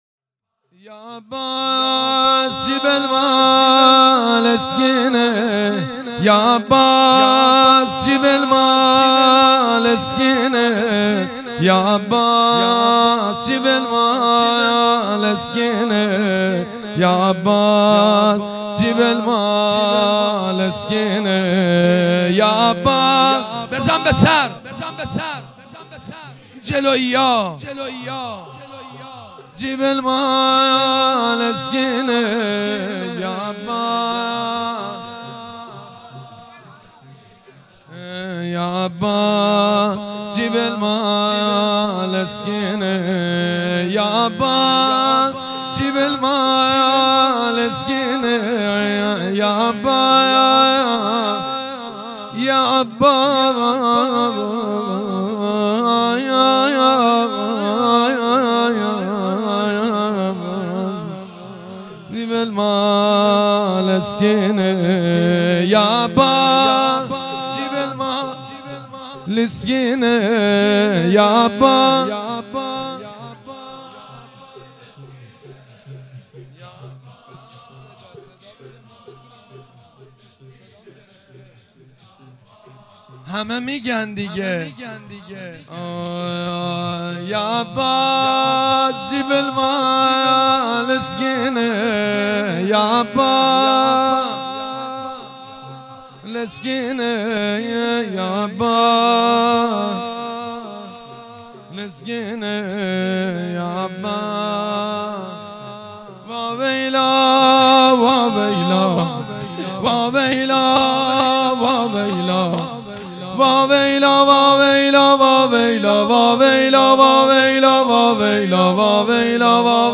حاج سید مجید بنی فاطمه یکشنبه 6 ابان 1397 موکب ریحانه الحسین سلام الله علیها
سبک اثــر شور مداح حاج سید مجید بنی فاطمه
مراسم اربعین